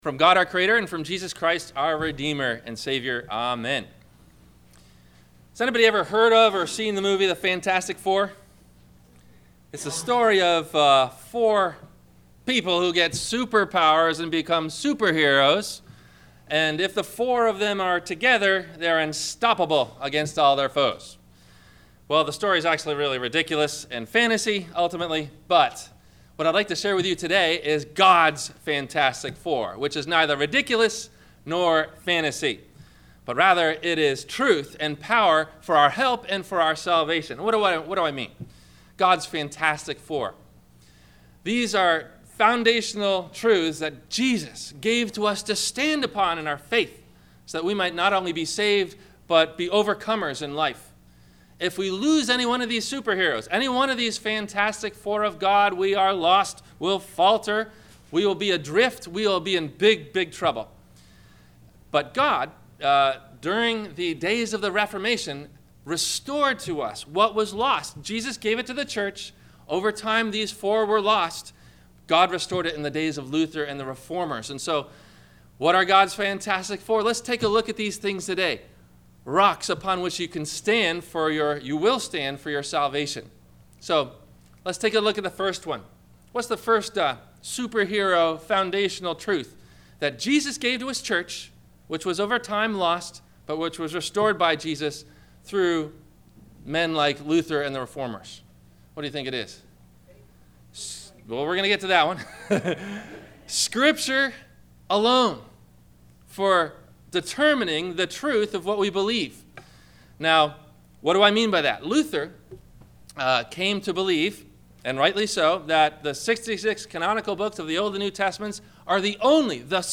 Martin Luther Got in Trouble Over One Little Word, What was It? (God’s Fantastic Four) – Sermon – October 25 2015